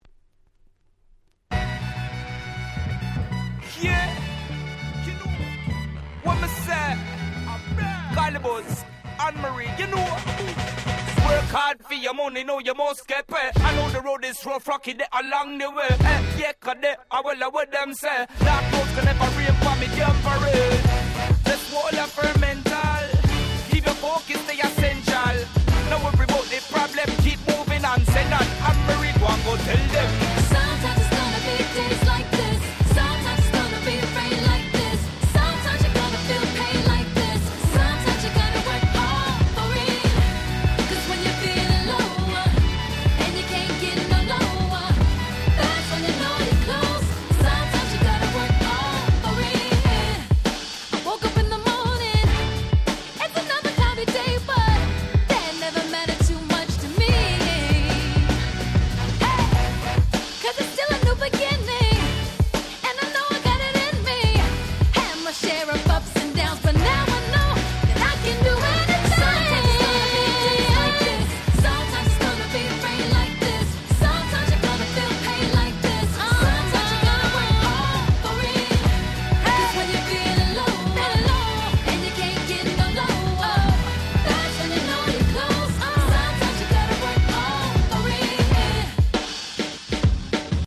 07' Nice Remix !!